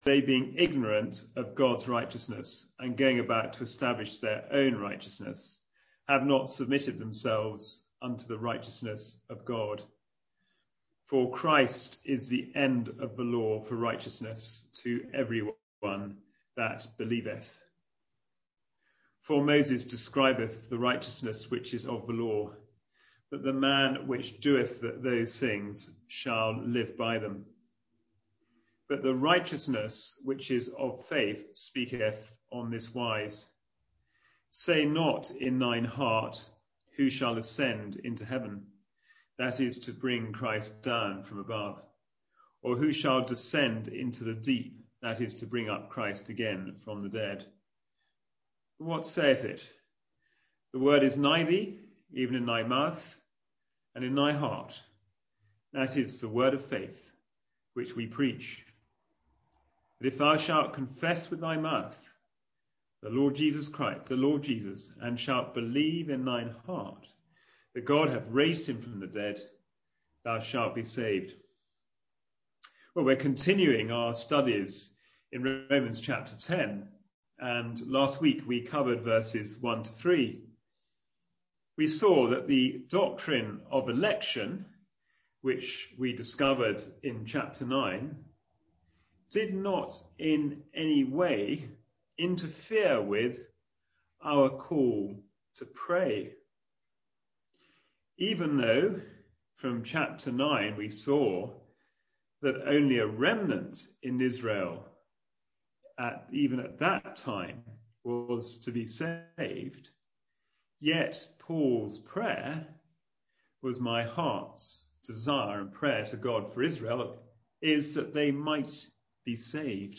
Passage: Romans 10:4-9 Service Type: Wednesday Bible Study